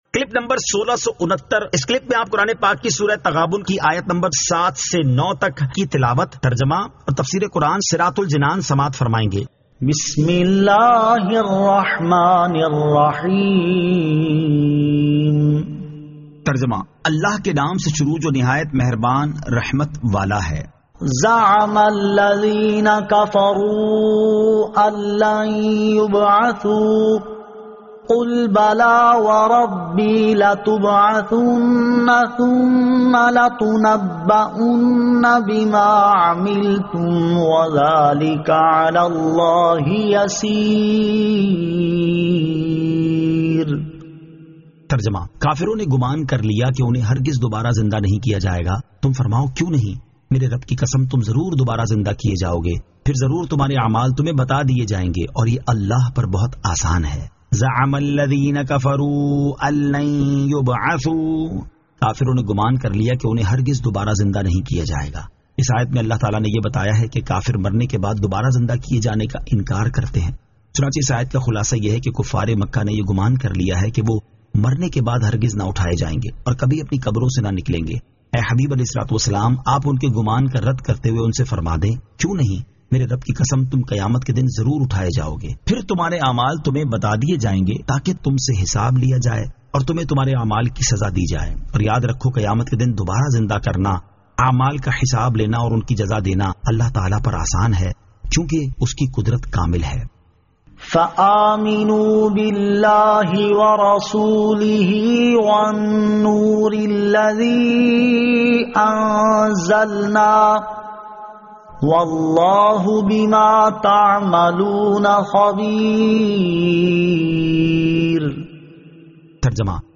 Surah At-Taghabun 07 To 09 Tilawat , Tarjama , Tafseer